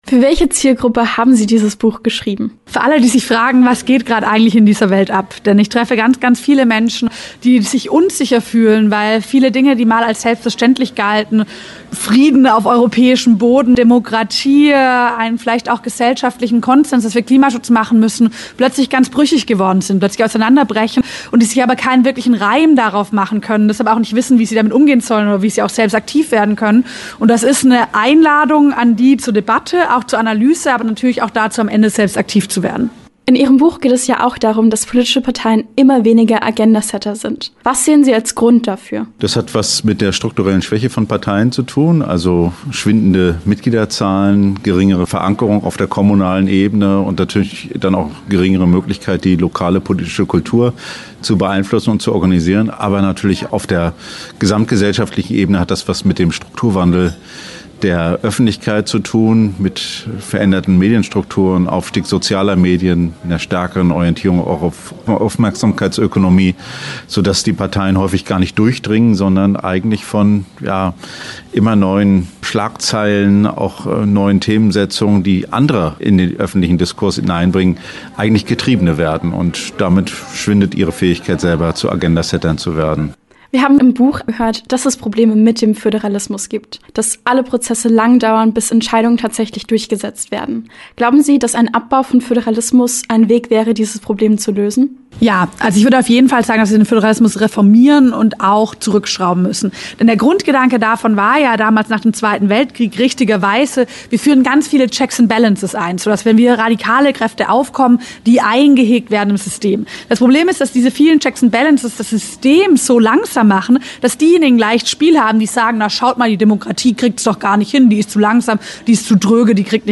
„Der große Umbruch“: Die Sachbuch-Autoren Ricarda Lang und Steffen Mau im Gespräch - Okerwelle 104.6
Interview-Umbruch-Lang-Mau_cas.mp3